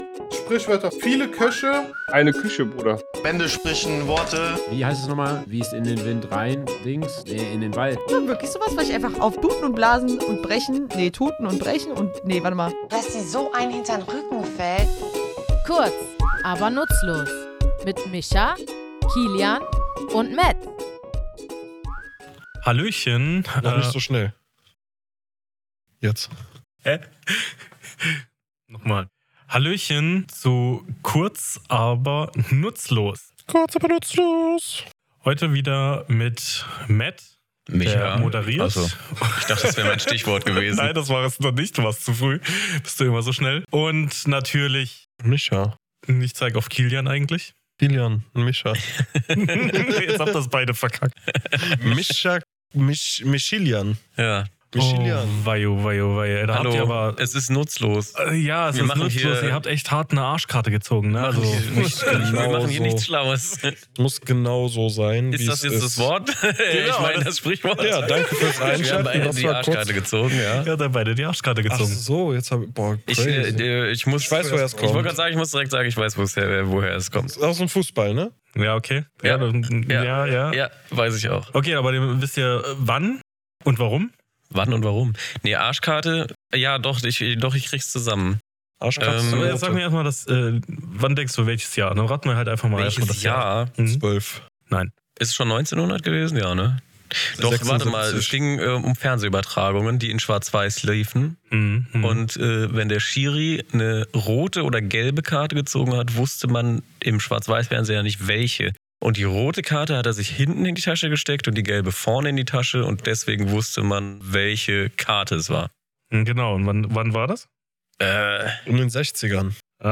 Comedy
Wir, drei tätowierende Sprachliebhaber, klären in unserem